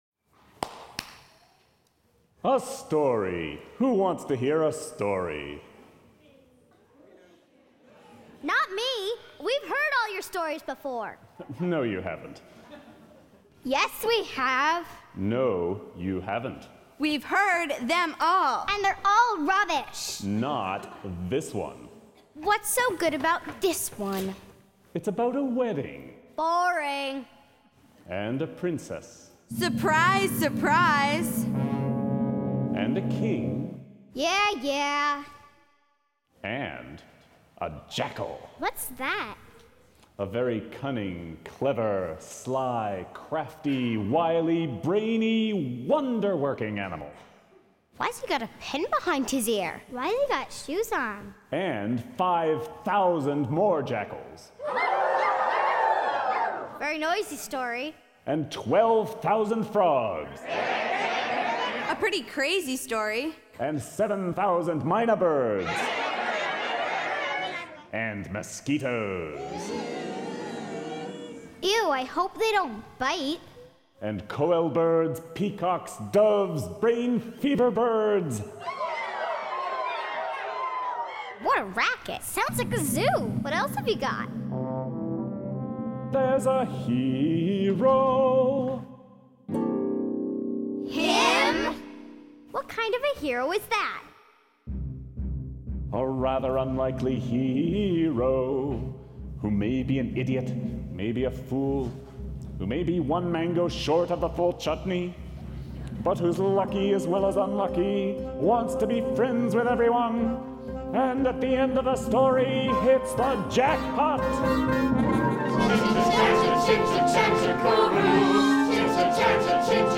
Performed by two intergenerational casts at:
Hear Scene 1 - Prologue sung by Story Teller, the Kids, and the chorus.